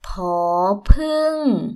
∨ poor ∧ pueng
poor-pueng.mp3